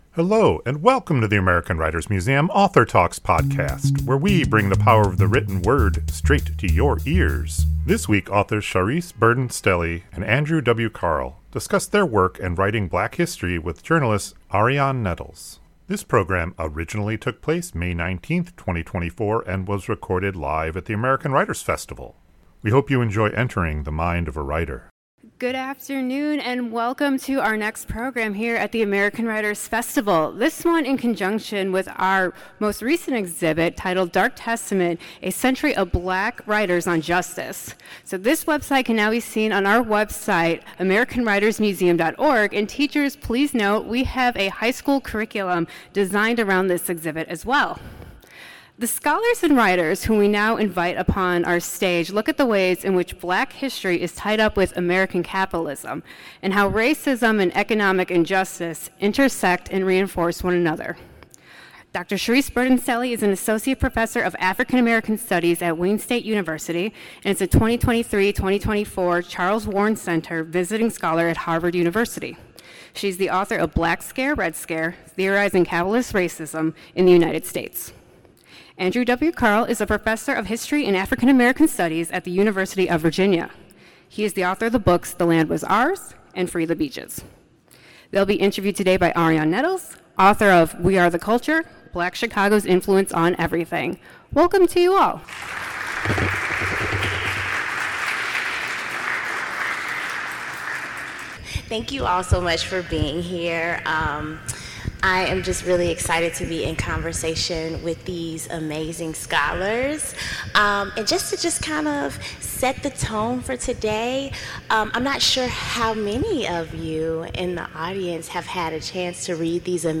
This conversation originally took place May 19, 2024 and was recorded live at the American Writers Festival.